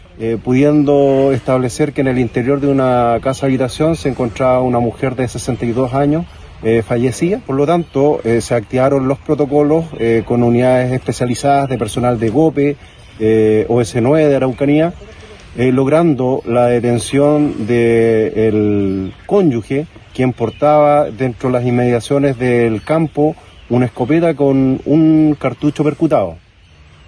La información fue confirmada por el jefe de la Novena Zona de Carabineros de La Araucanía, general Patricio Yáñez, quien además detalló que el detenido era pareja de la víctima.